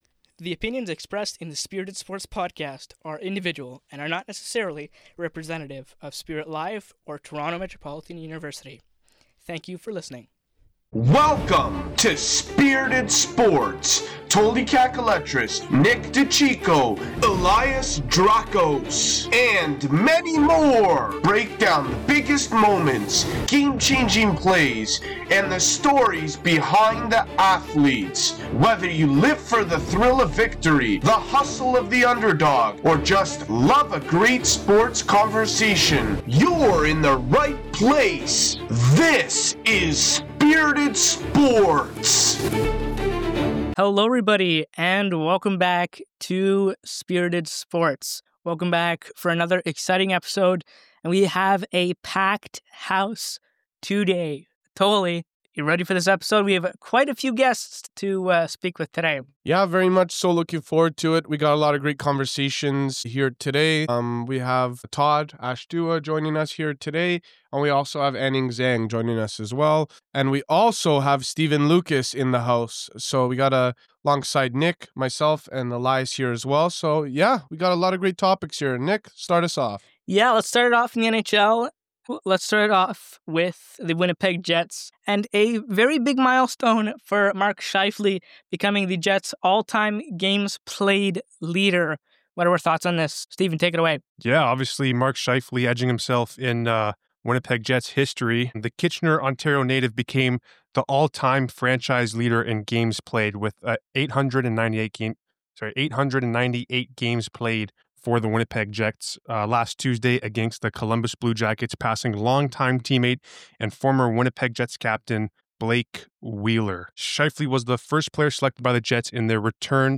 Celebrating Mark Scheifele’s historic milestone with the Winnipeg Jets, and the dynamic duo of McDavid and Draisaitl making NHL history. We also explore Macklin Celebrini’s incredible achievements and LeBron James’ triumphant return to the court. From the ice to the court, and even the soccer pitch, this episode is packed with thrilling sports moments and insightful discussions.